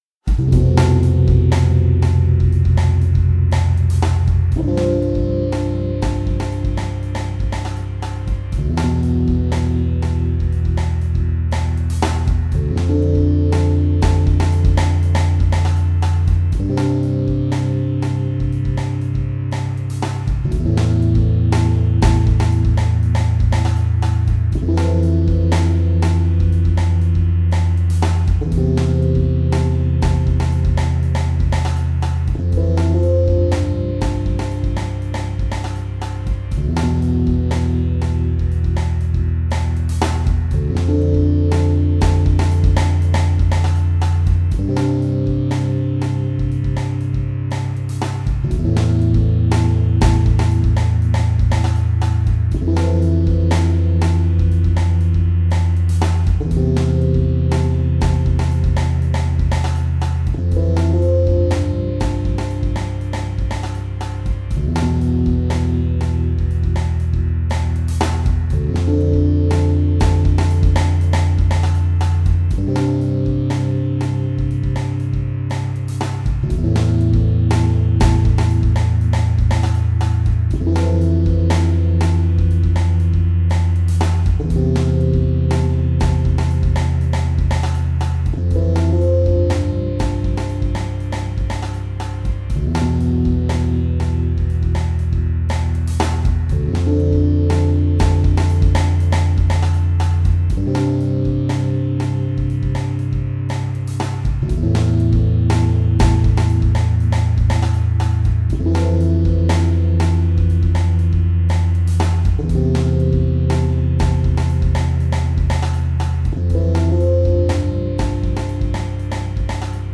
Airplane Flying (Relaxed Looping BG Music)